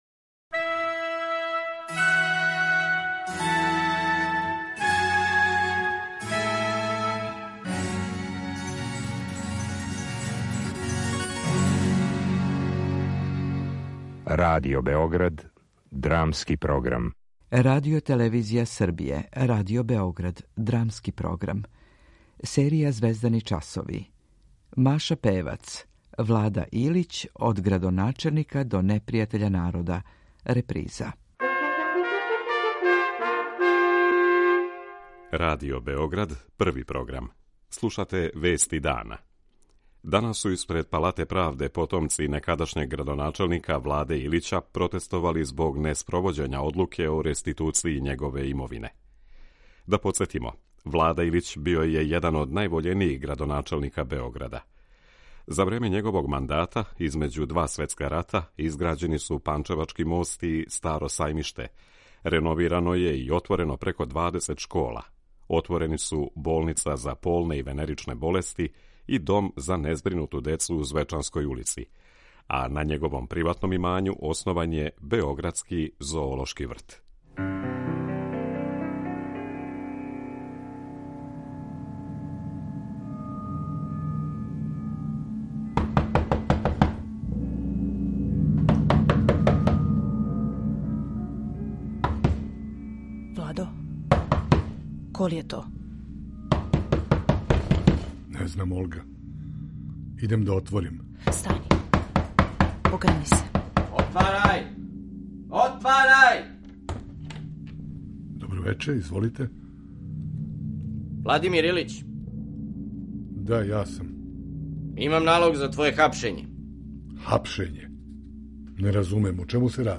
Драмски програм